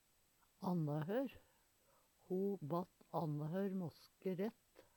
annahør - Numedalsmål (en-US)